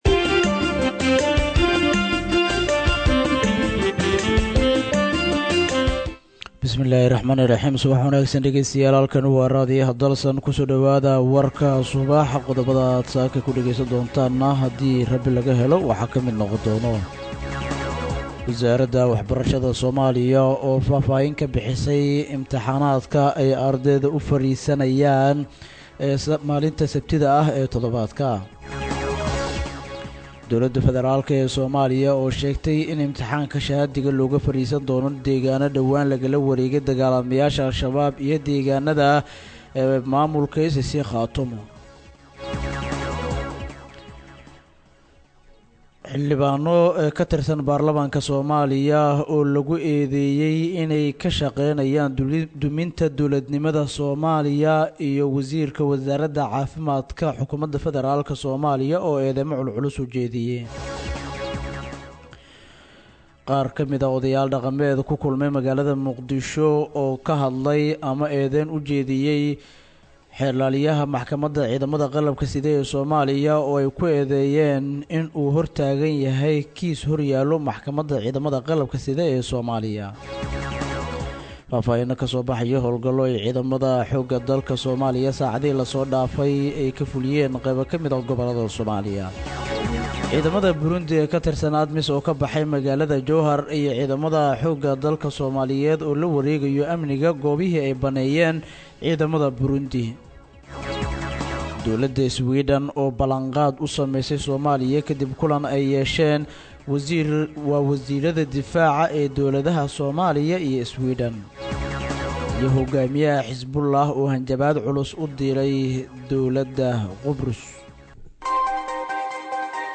Dhageyso:-Warka Subaxnimo Ee Radio Dalsan 21/06/2024